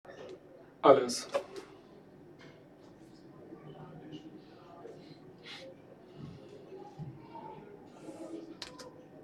MS Wissenschaft @ Diverse Häfen
Standort war das Wechselnde Häfen in Deutschland. Der Anlass war MS Wissenschaft